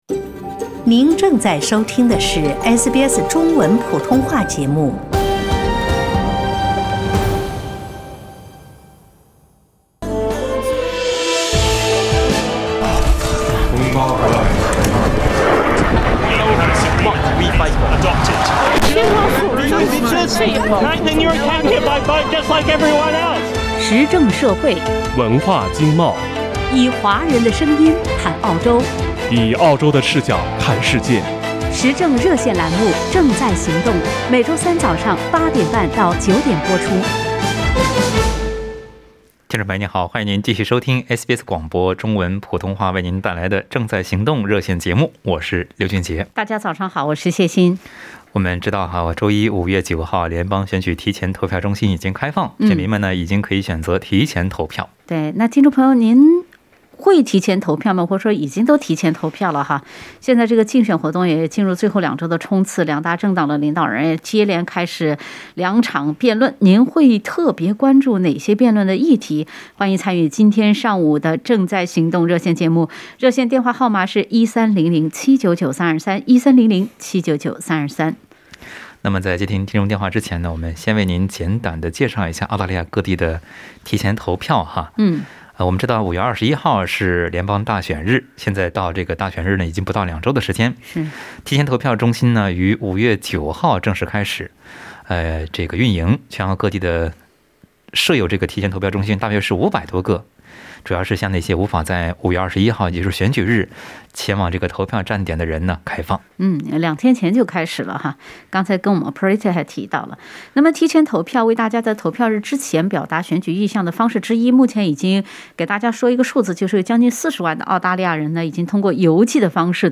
在节目中，听友们还就经济管理、澳中关系、民生等其关注的话题表达了看法。